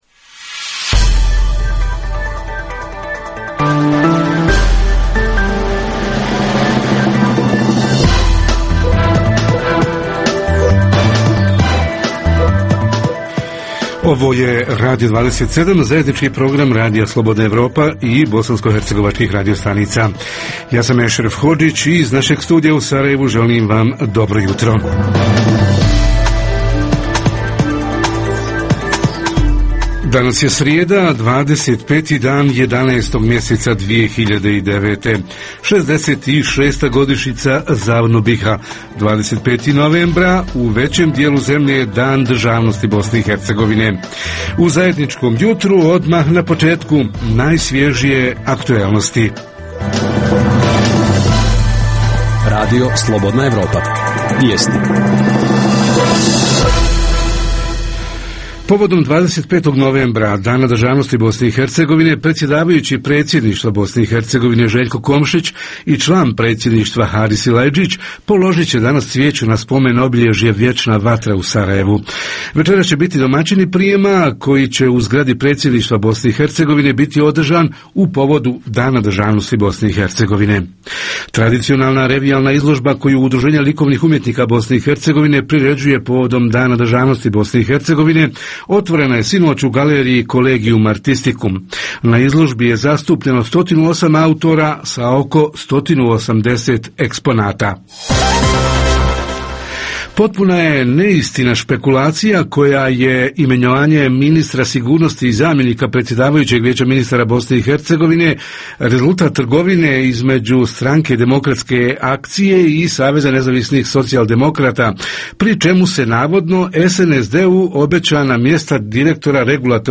Šta i kako da Bosnu i Hercegovinu svi njeni narodi i građani prihvate kao svoju državu? Reporteri iz cijele BiH javljaju o najaktuelnijim događajima u njihovim sredinama.